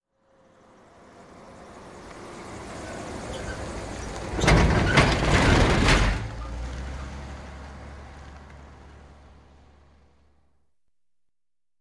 Car Tires Over Cattle Guard 2x